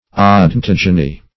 Meaning of odontogeny. odontogeny synonyms, pronunciation, spelling and more from Free Dictionary.
Search Result for " odontogeny" : The Collaborative International Dictionary of English v.0.48: Odontogeny \O`don*tog"e*ny\ ([=o]`d[o^]n*t[o^]j"[-e]*n[y^]), n. [Odonto- + root of Gr. gi`gnesqai to be born: cf: F. odontog['e]nie.]